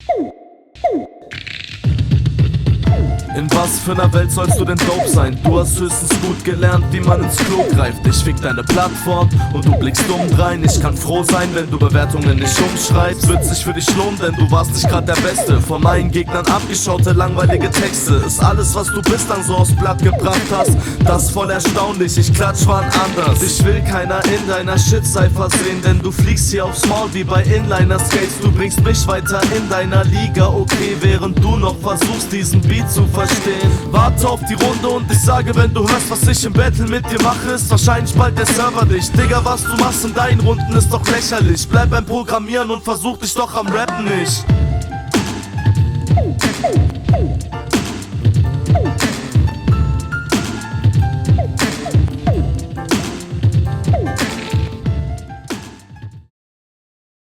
Also Flow klingt routiniert, dennoch finde ich es anstrengend anzuhören und man muss …